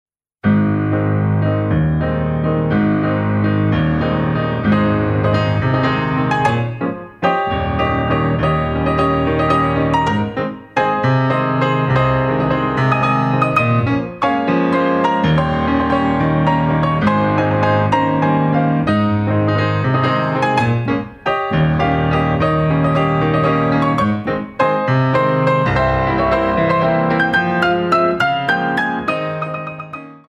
Grands sauts